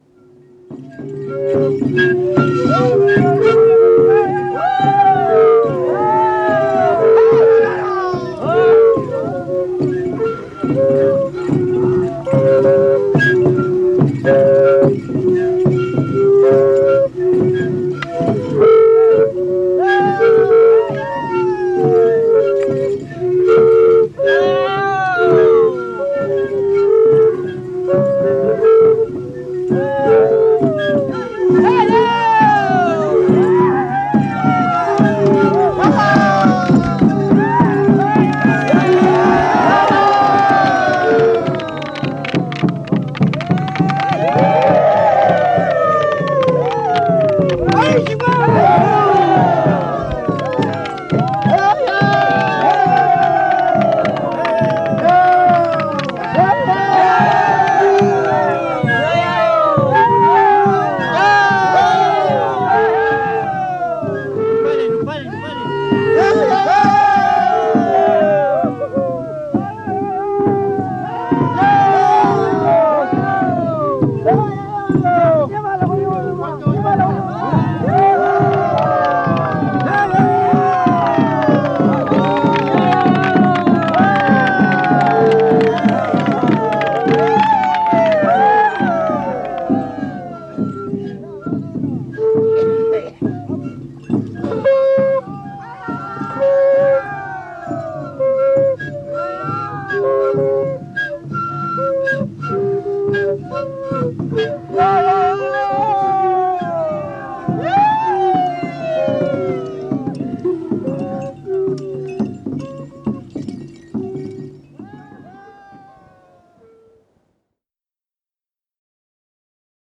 Música mapuche (Comunidad de Collinque, Lumaco)
Música tradicional